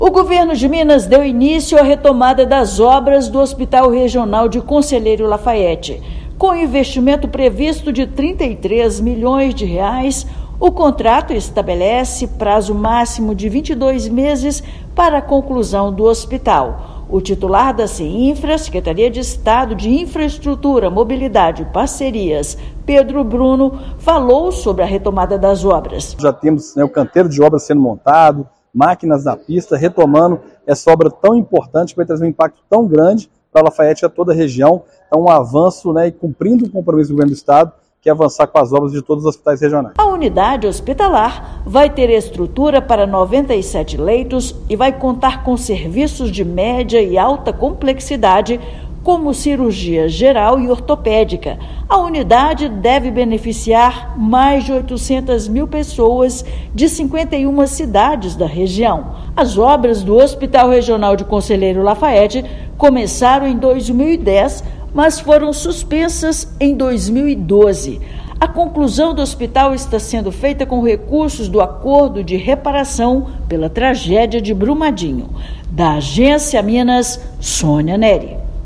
Com cerca de cem leitos, unidade vai ampliar o acesso a serviços especializados, reduzir deslocamentos de pacientes e fortalecer a regionalização da saúde no estado. Ouça matéria de rádio.